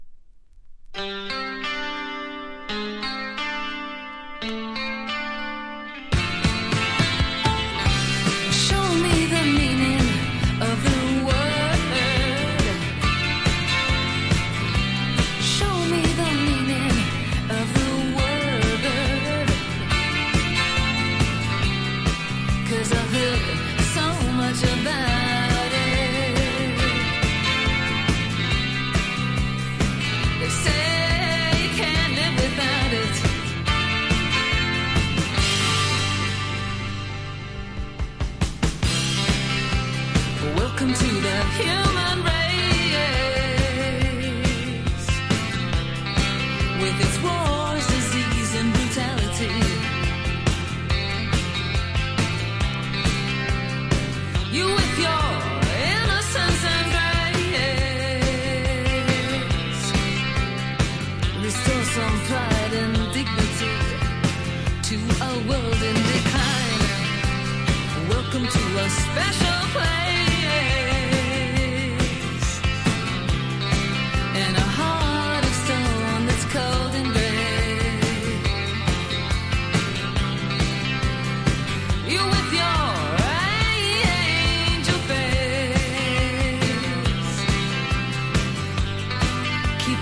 80’s ROCK / POPS